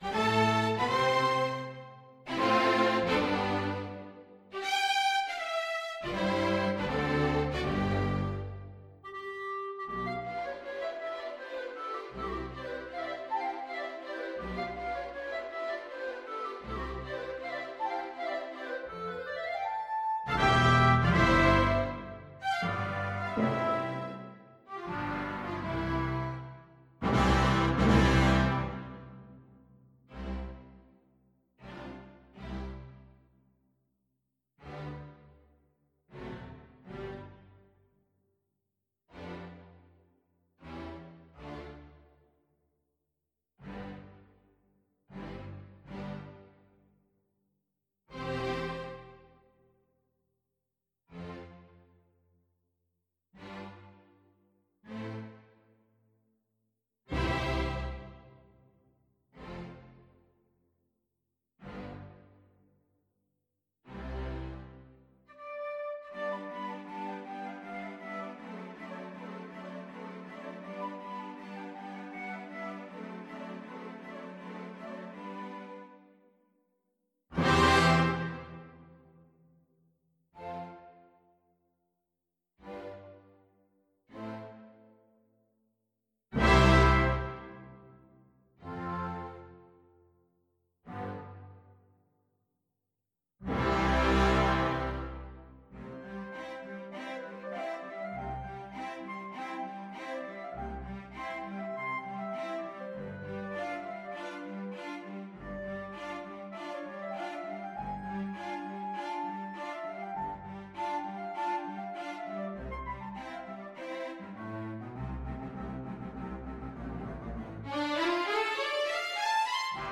Allegro Moderato =80 (View more music marked Allegro)
Classical (View more Classical Flute Music)